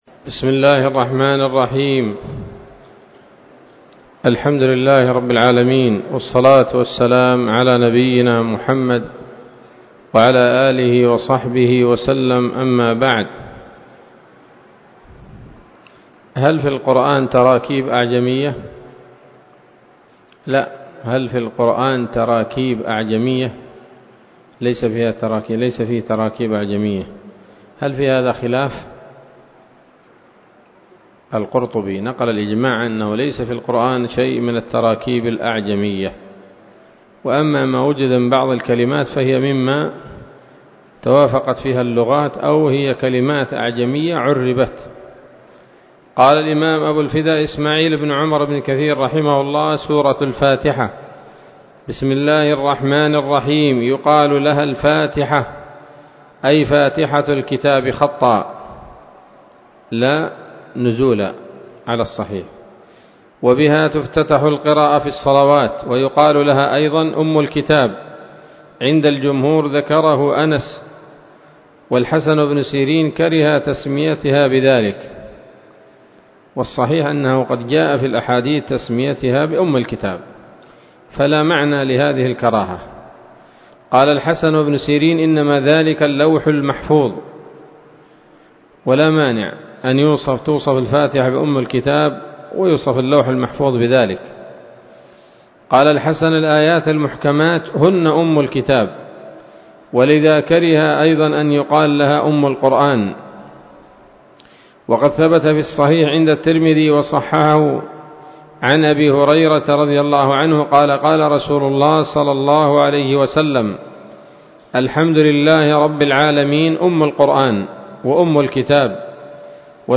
الدرس الثاني من سورة الفاتحة من تفسير ابن كثير رحمه الله تعالى